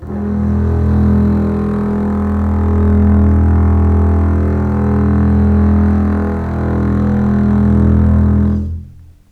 E1 LEG MF  L.wav